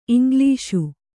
♪ iŋglīṣu